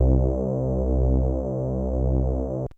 Index of /90_sSampleCDs/AMG - Global Trance Mission VOL-1/Partition C/Bass. blips 4
PRO5FRETLESS.wav